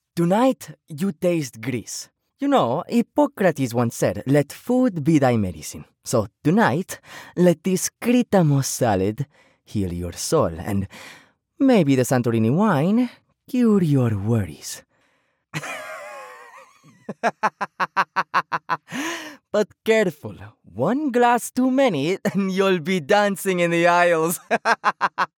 Accent Sample
Accents
Greek Accent